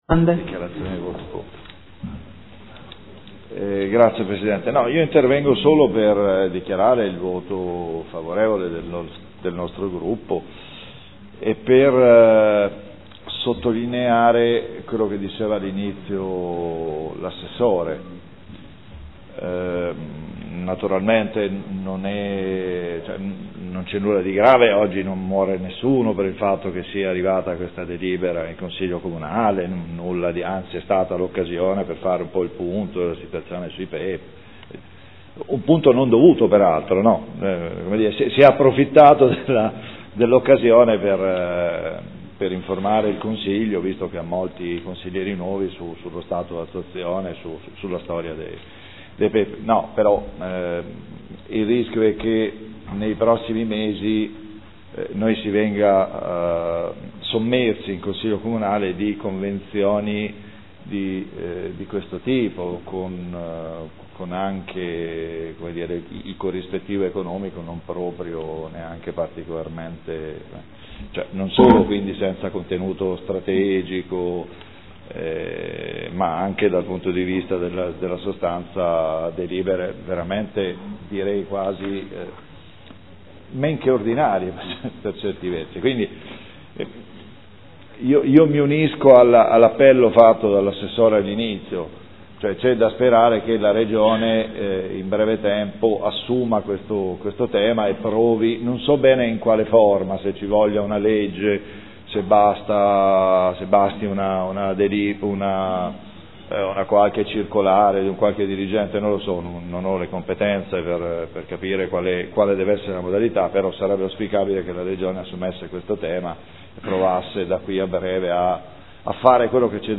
Seduta del 21/05/2015 Dichiarazione di voto.